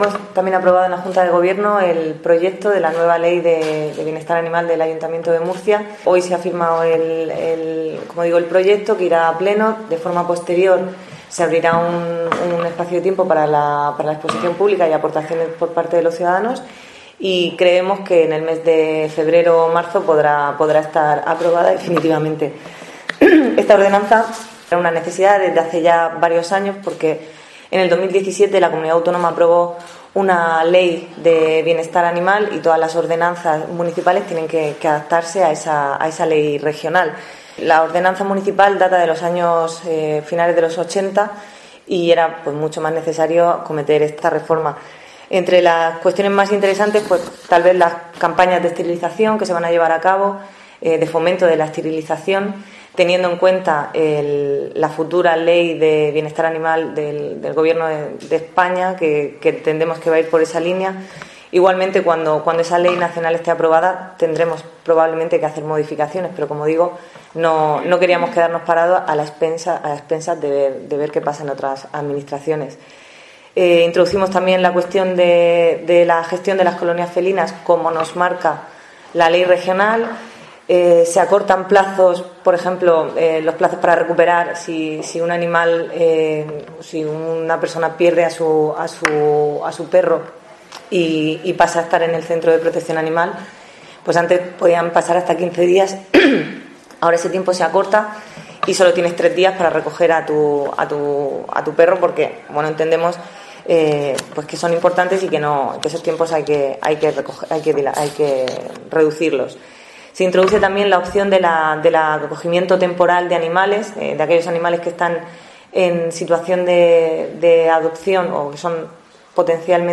Esther Nevado, concejala de Salud del Ayuntamiento de Murcia
El proyecto, ha explicado la concejala de Salud, Esther Nevado, en la rueda de prensa posterior a la reunión semanal de la Junta de Gobierno, nace de la necesidad de adecuar la actual normativa, vigente desde finales de los años 80, a la ley regional, que se reformó en 2017.